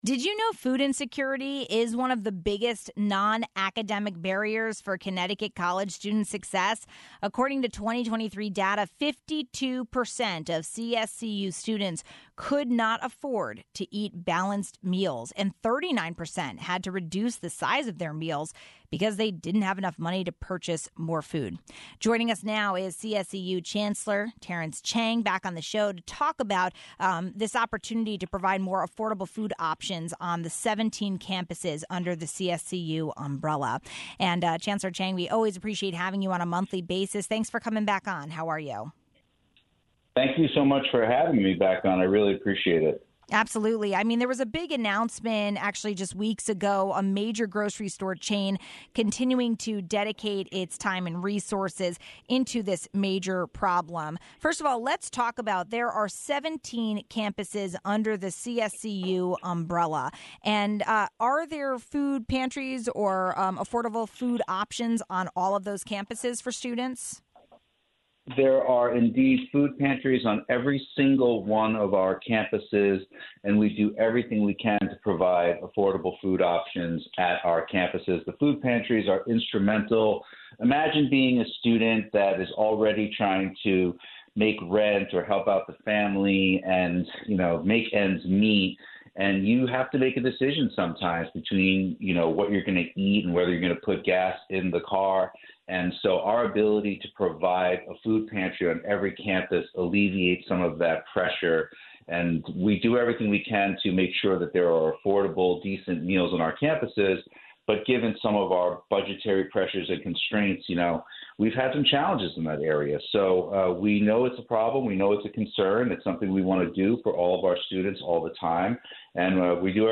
We spoke with CSCU Chancellor Terrence Cheng about providing affordable food options on the 17 campuses under the CSCU umbrella. We also talked about the recently approved, new Transfer Credit Alignment Policy.